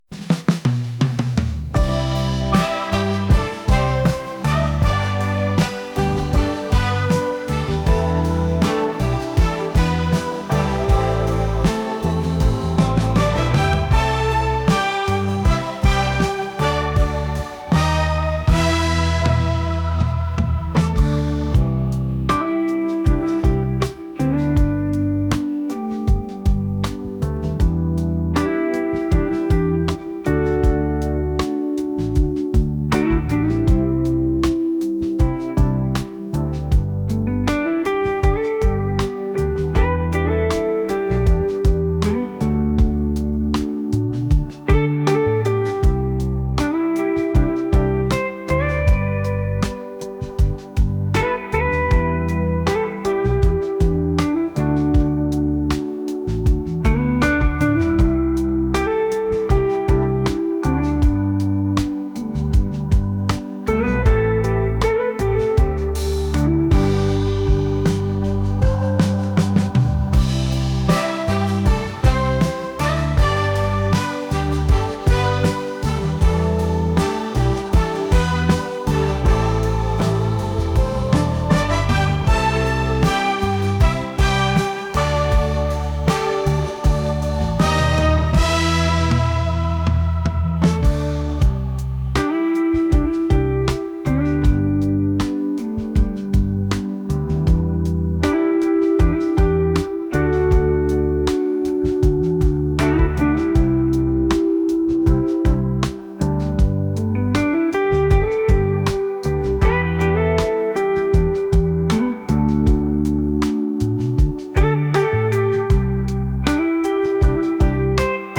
pop | soulful